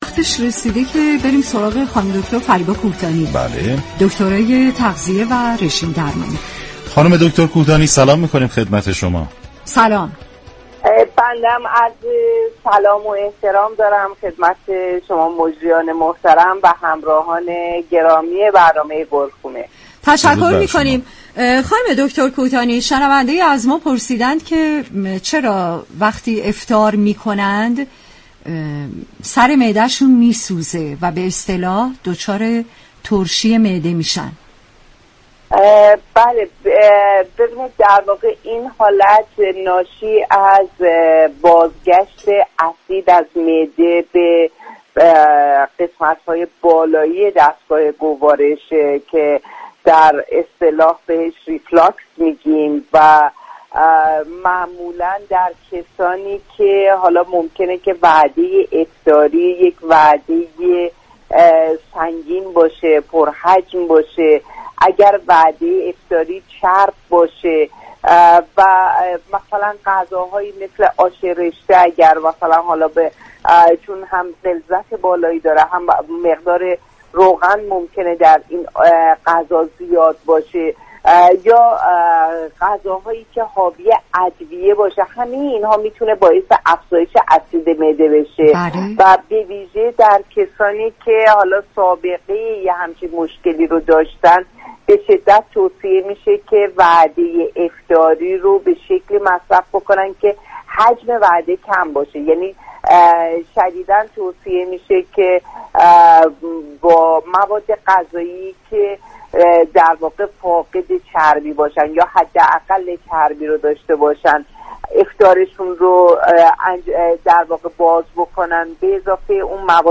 شما می توانید از طریق فایل صوتی پیوست شنونده بخشی از برنامه "گلخونه" رادیو ورزش باشید.